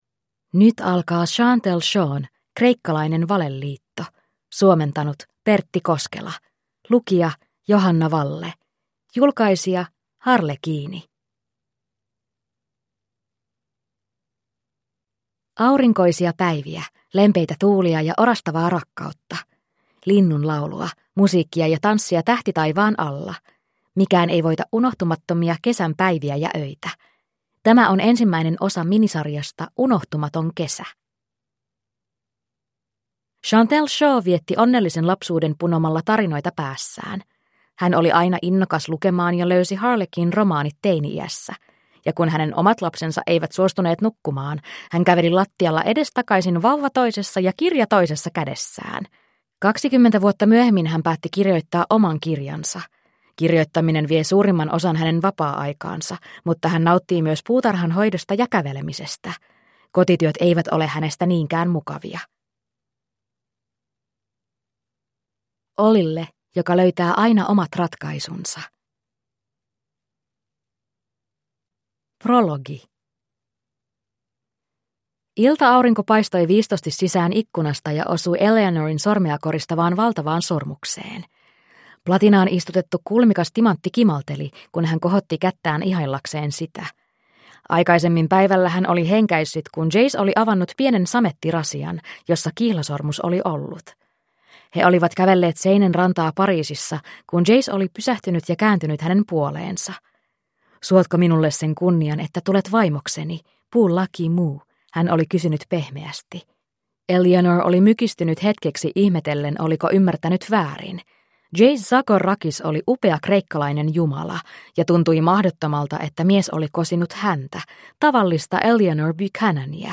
Kreikkalainen valeliitto (ljudbok) av Chantelle Shaw